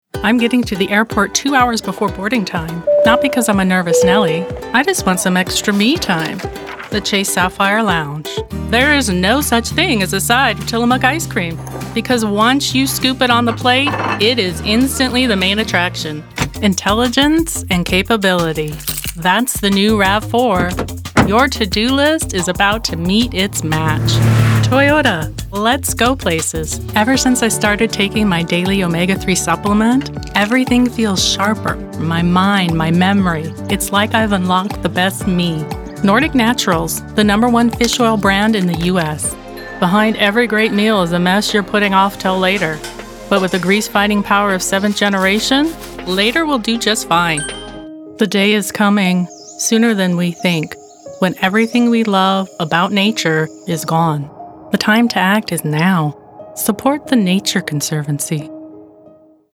English - USA and Canada
Young Adult
Middle Aged